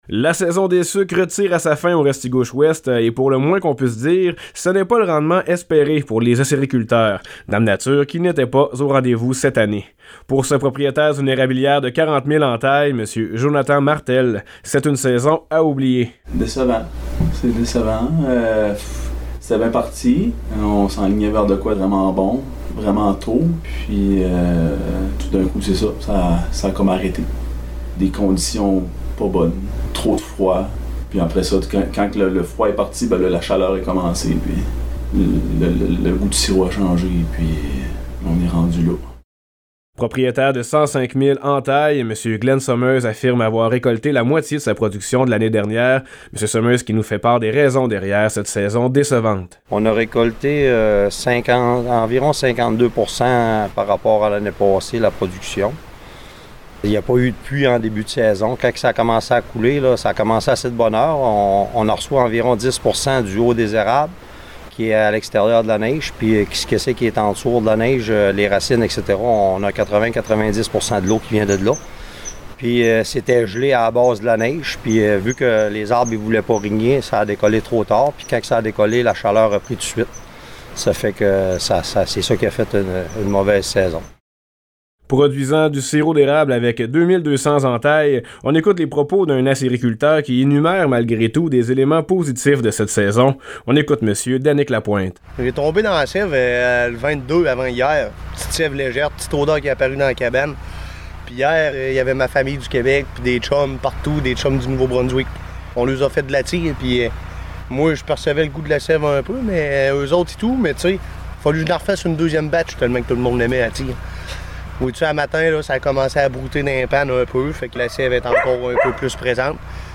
Le reportage
sur les ondes du FM90 Route 17.